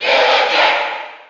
Villager_Cheer_NTSC_SSB4.ogg.mp3